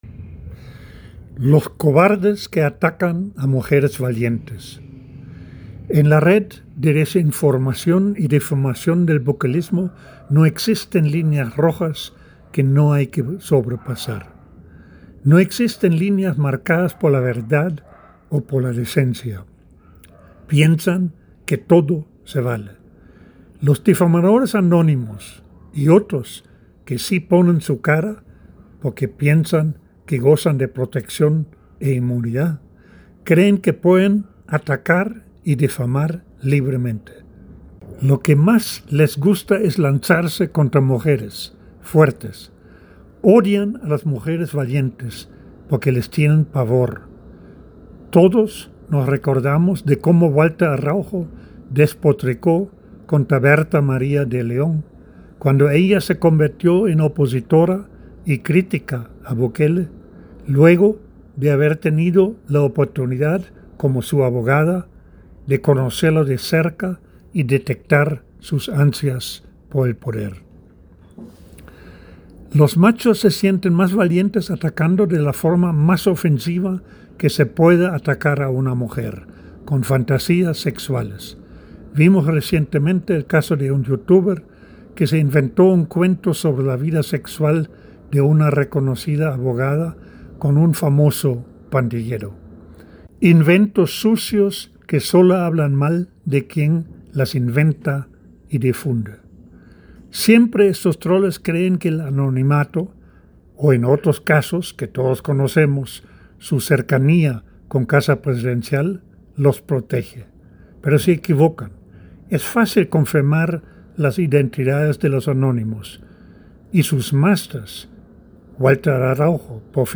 En la voz del autor